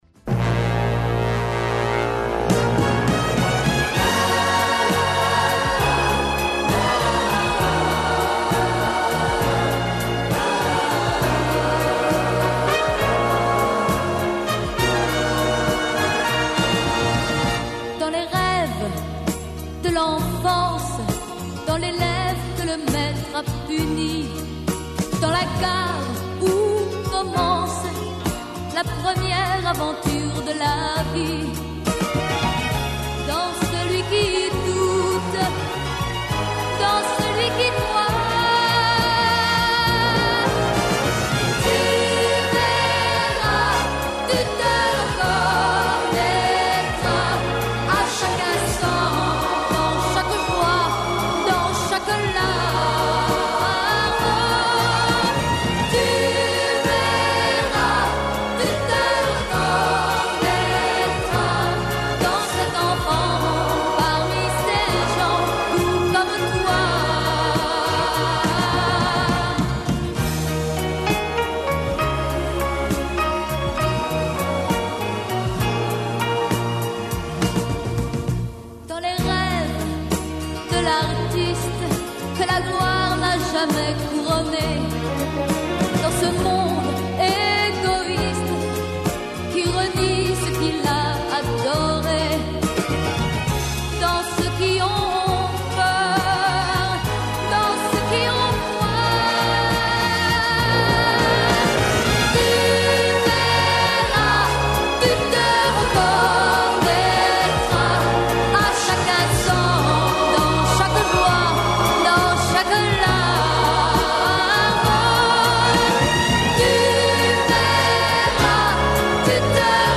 У данашњој емисији преслушавамо победничке песме "Евросонга" претходних година, а разговарамо и са прошлогодишњим пресдтавником Грчке на "Песми Евровизије", Јоргосом Алкеосом.
Емисија из домена популарне културе.